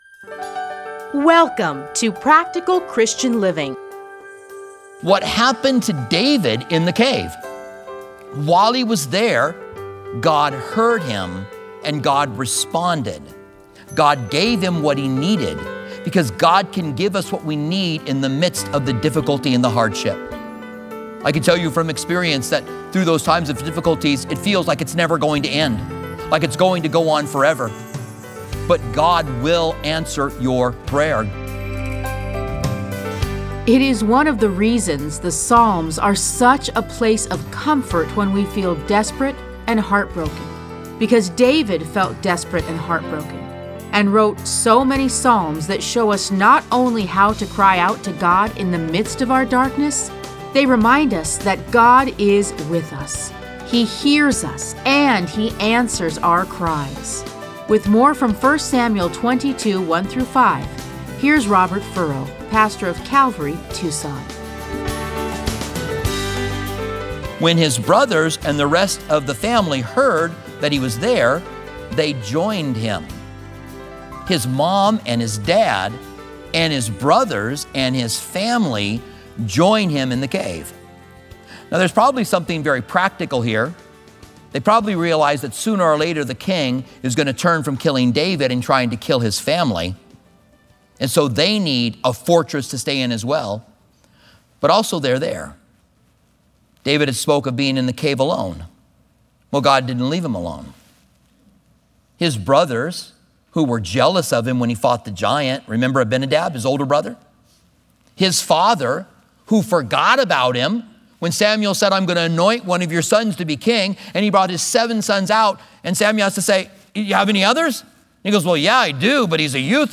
Listen to a teaching from 1 Samuel 22:1-5.